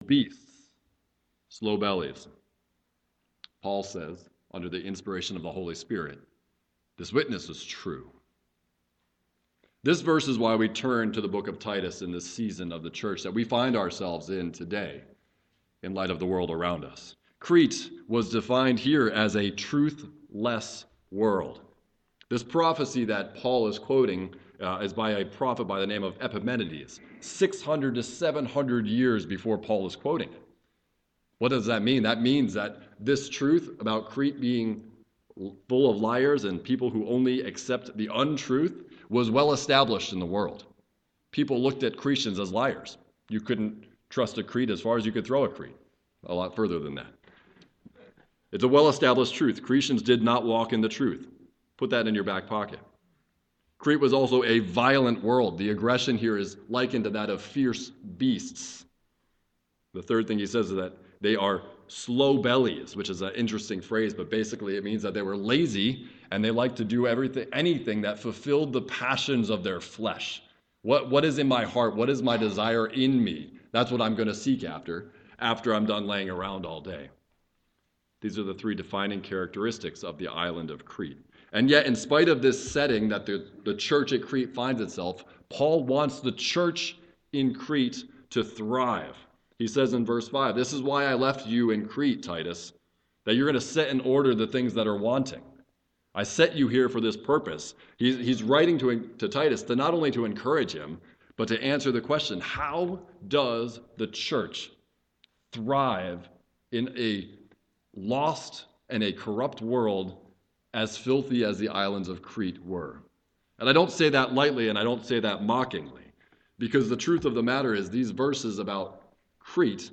Morning Worship – 01/08/23 Titus – Introduction & Book Outline – Paul: The Sent Slave Text